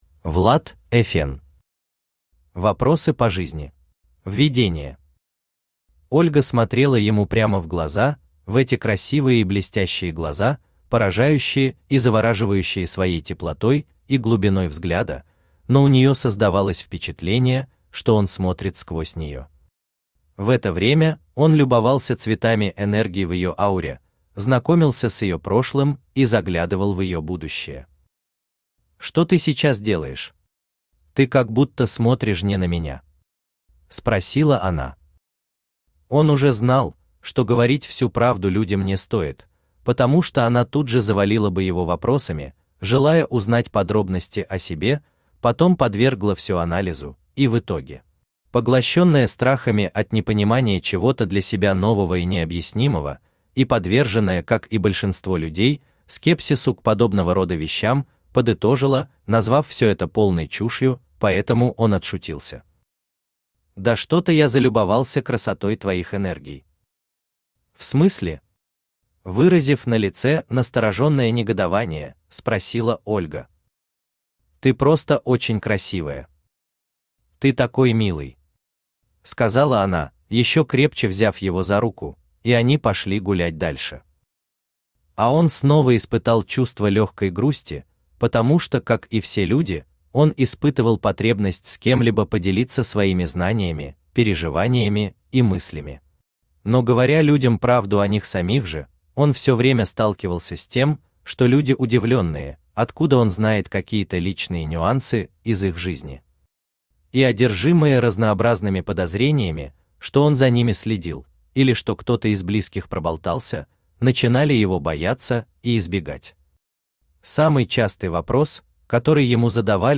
Аудиокнига Вопросы по жизни | Библиотека аудиокниг
Aудиокнига Вопросы по жизни Автор Влад Эфен Читает аудиокнигу Авточтец ЛитРес.